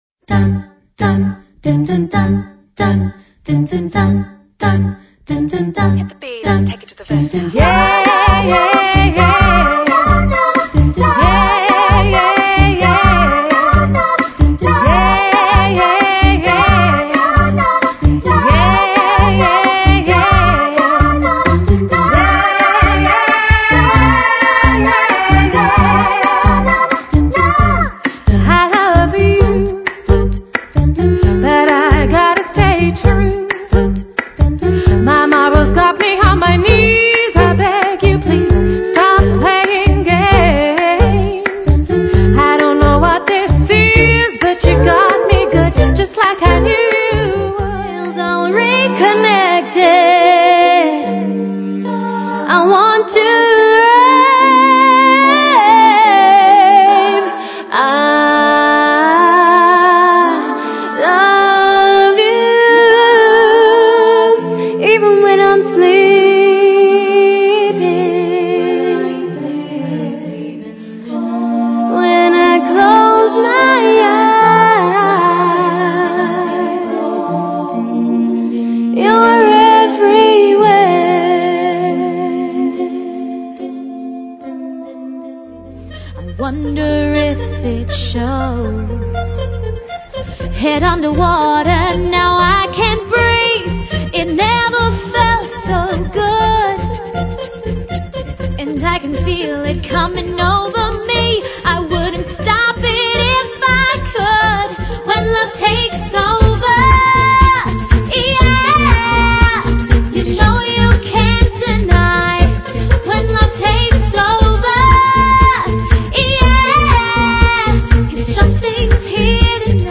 Acappella girl group
rely solely on their voices to create their unique sound